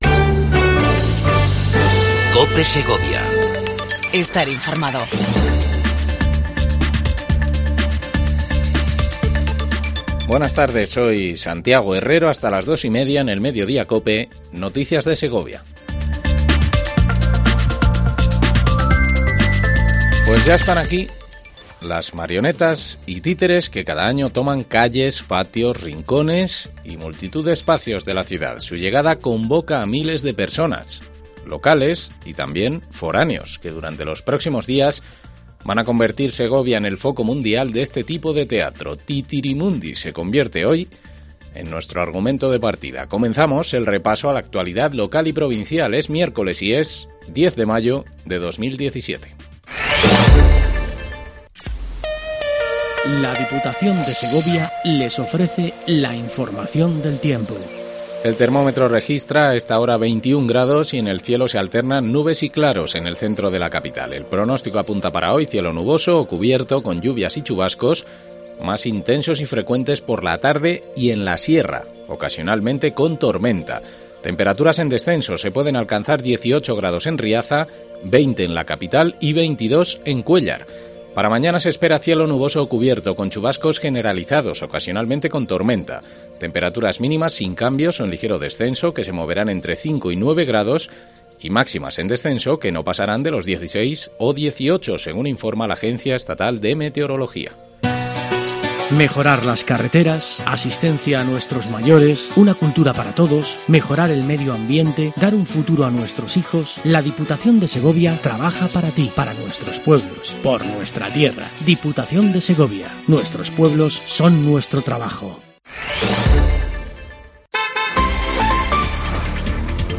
INFORMATIVO MEDIODIA COPE EN SEGOVIA 10 05 17